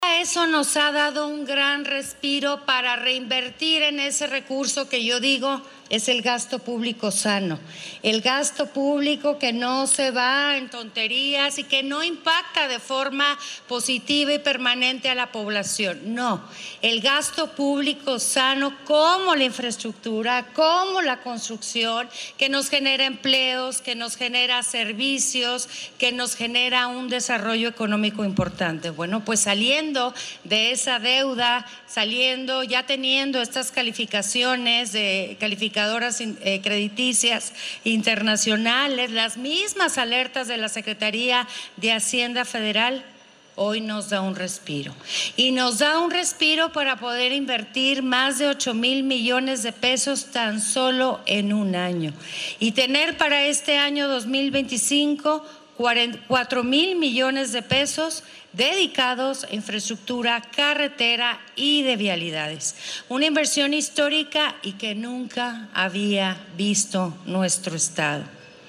Chihuahua, Chih.- En el marco de la inauguración de la Feria Nacional de la Construcción Edición 2024, en la ciudad de Chihuahua, organizada por la Cámara Mexicana de la Industria de la Construcción (CMIC), la gobernadora del Estado, María Eugenia Campos, anunció una inversión de 4 mil mdp, destinados a rehibilitación, recarpeteo, bacheo y construcción de tramos carreteros, así como de carreteras y avenidas urbanas.
Ante miembros de la Cámara Mexicana de la Industria de la Construcción (CMIC), la mandataria afirmó que la inversión en obra pública significa el despliegue de recursos, que llegan a las familias a través de los empleos que generan cada proyecto.
AUDIO: MARÍA EUGENIA CAMPOS, GOBERNADORA DEL ESTADO DE CHIHUAHUA